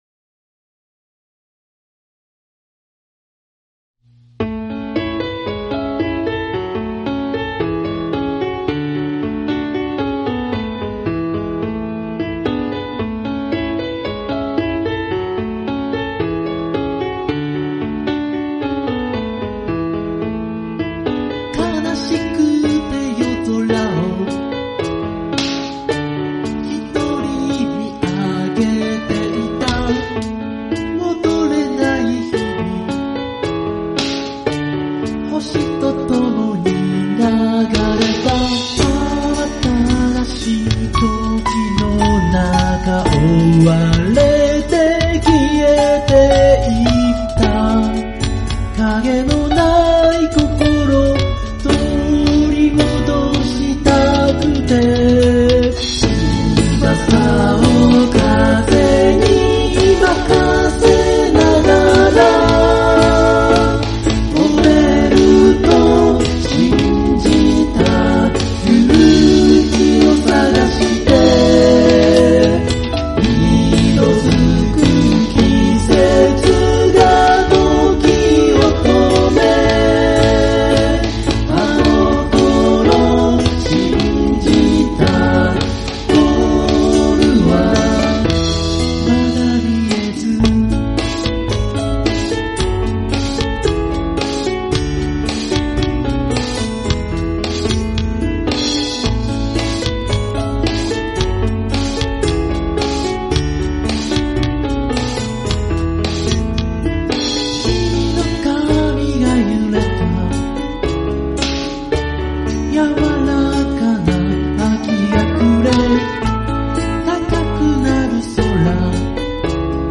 Vocal、A.Guitar
Chorus,E.Guitar,Bass,Keyboard,Drums
コーラスはアルフィーのように厚く、間奏ではオフコースをイメージした。